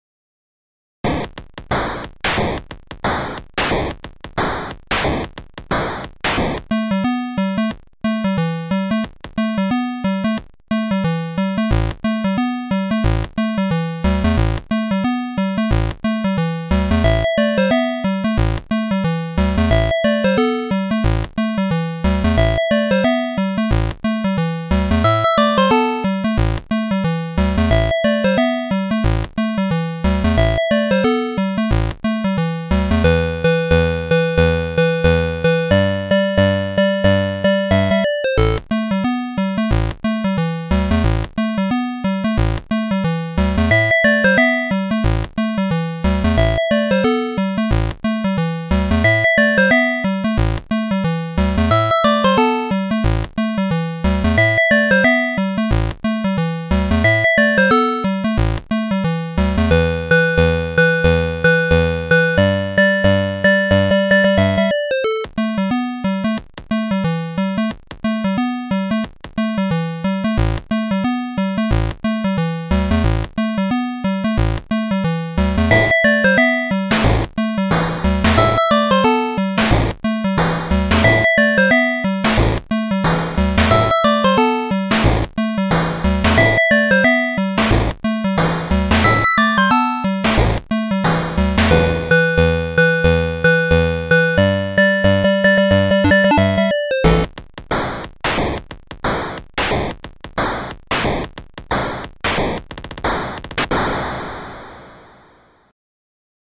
Atari-ST Emulation